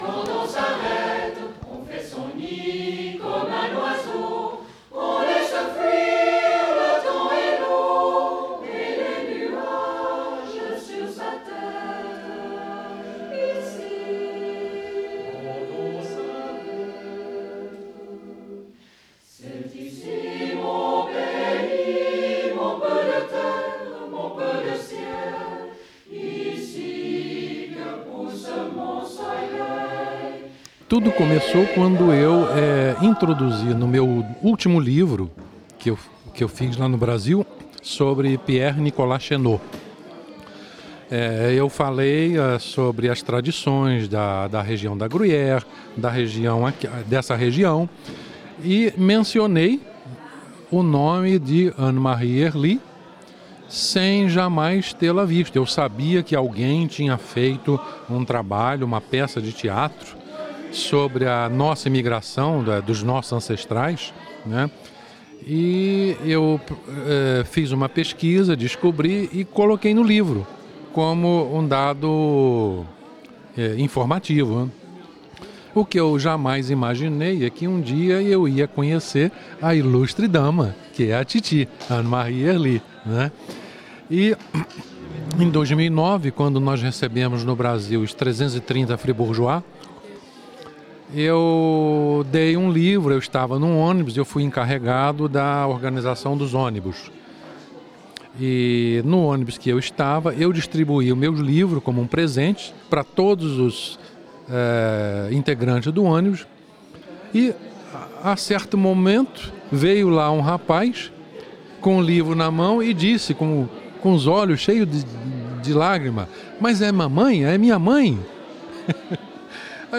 Introdução: coral "Lè Tserdziniolè".